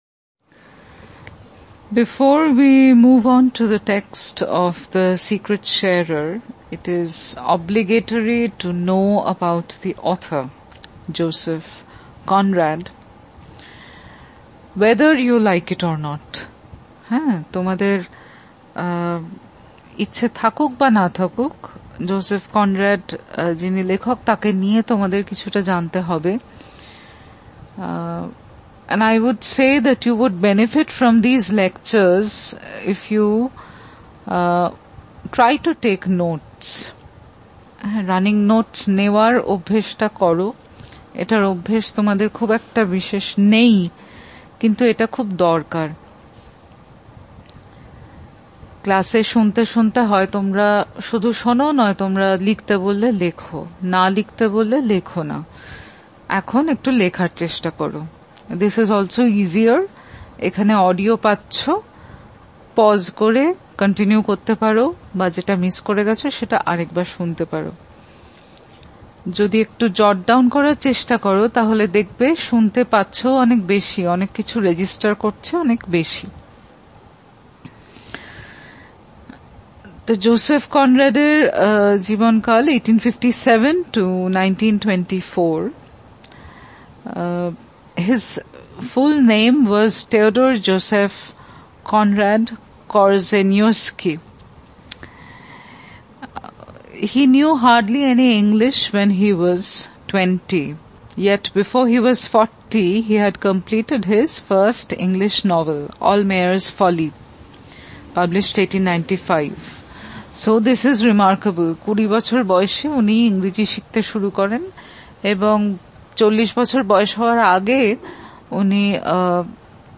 DB Lec 3 Joseph Conrad.mp3